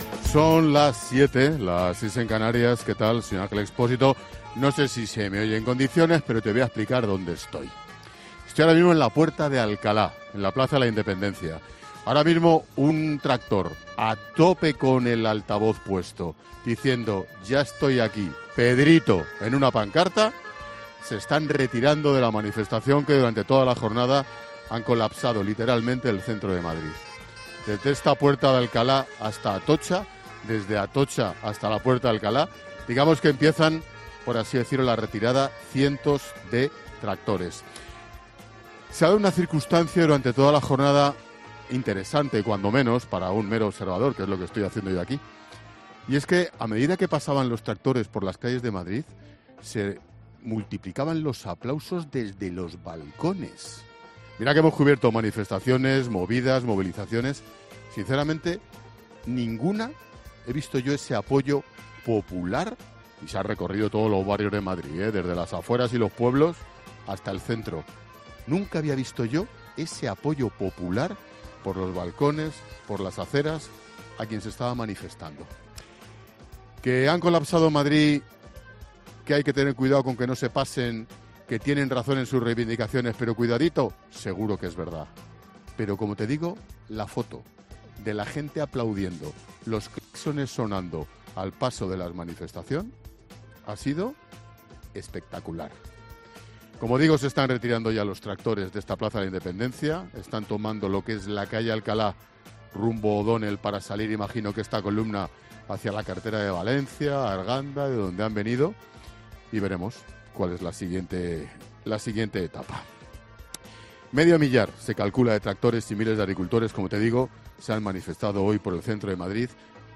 El comunicador de COPE arranca el programa este miércoles desde el que ha sido el punto caliente de las manifestaciones
El director de La Linterna, arrancaba el programa este miércoles desde el que ha sido el centro neurálgico de las protestas del campo en la capital: la Puerta de Alcalá.